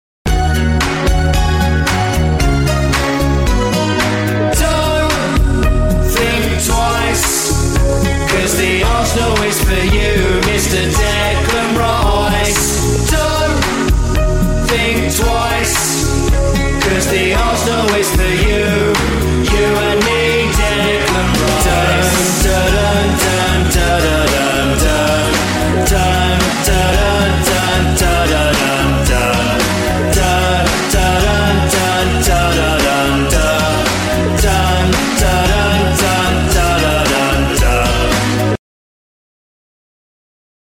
It's a geat Arsenal Song and makes a brilliant chant.